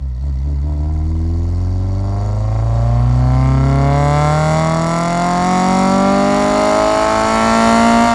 rr3-assets/files/.depot/audio/Vehicles/4cyl_01/4cyl_01_accel.wav